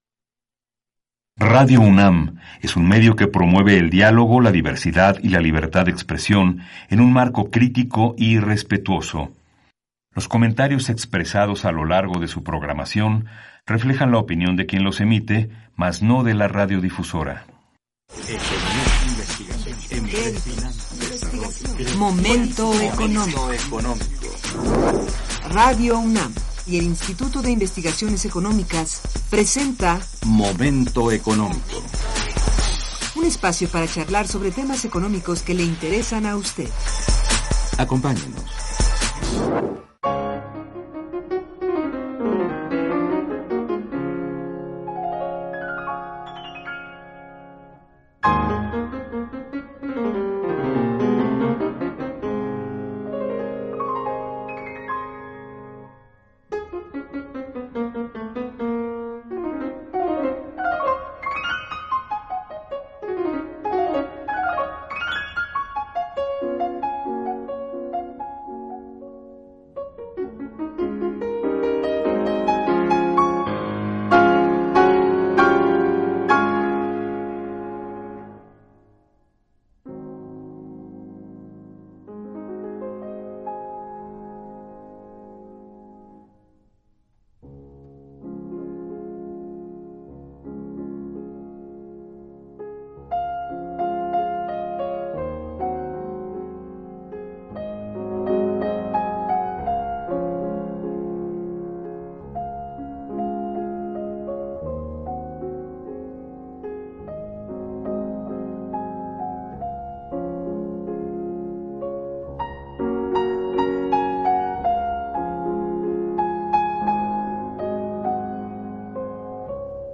Nuestros invitados compartieron con nosotros su mas reciente investigación, relacionada con el patrón migratorio contemporáneo y un análisis muy pertinente acerca de como ubicar la concentración de la fuga de talentos de México, hacia otras latitudes, la metodología que usaron se basó en el estudio de los sectores económicos más importantes de los países subdesarrollados, destinos buscados con mayor frecuencia por parte de nuestra mano de obra calificada, todo esto dentro de lo mas importante.